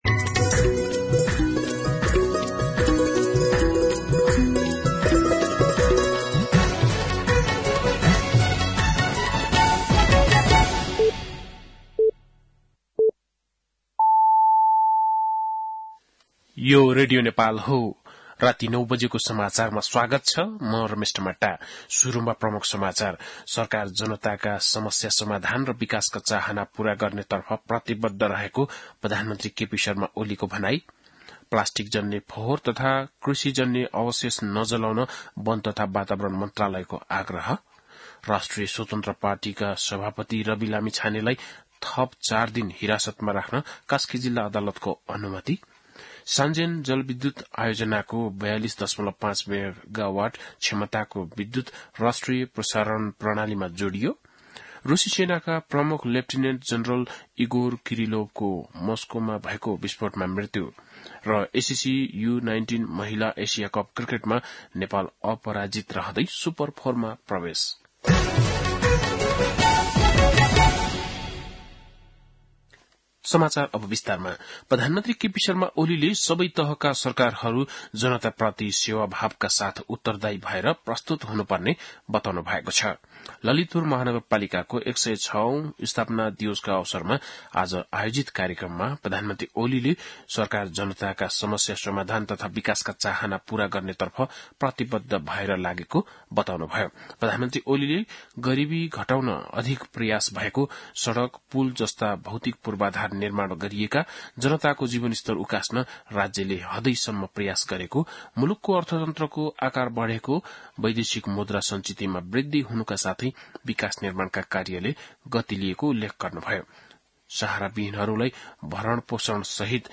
बेलुकी ९ बजेको नेपाली समाचार : ३ पुष , २०८१
9-PM-Nepali-News-9-2.mp3